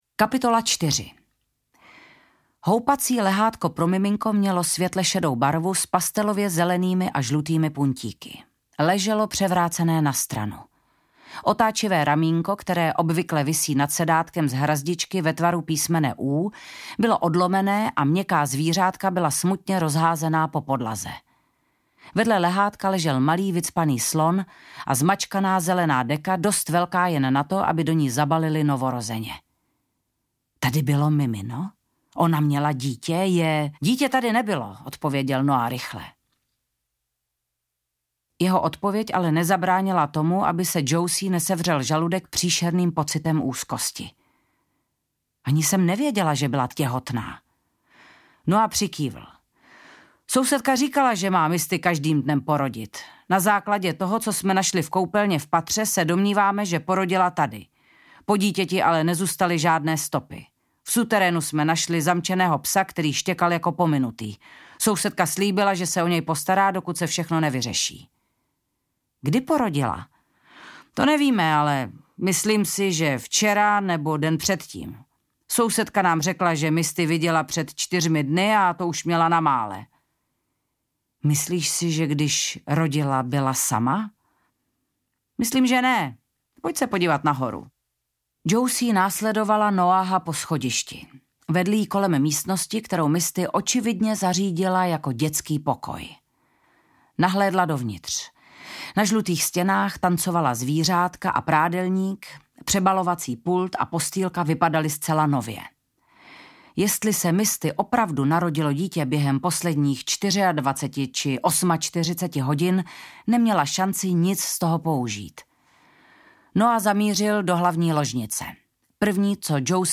Dívka beze jména audiokniha
Ukázka z knihy
• InterpretVanda Hybnerová